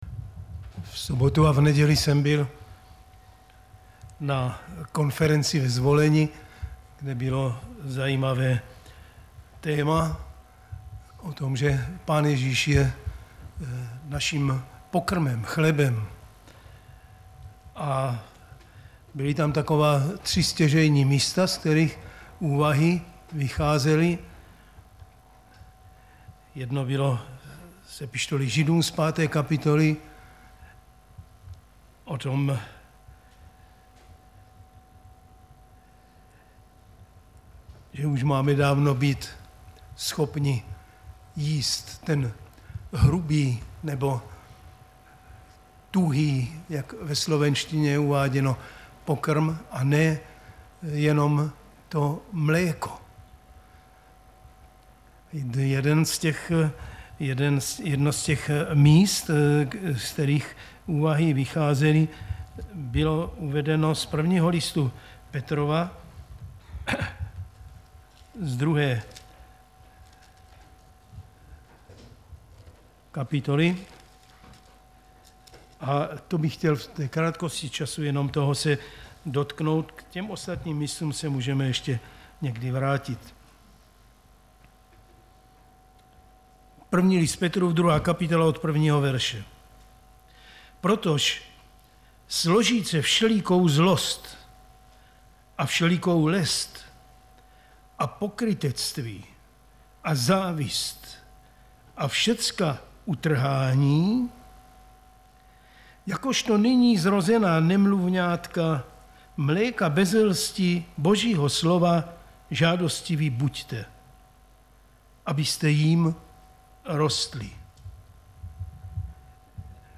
Záznamy z bohoslužeb